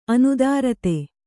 ♪ anudārate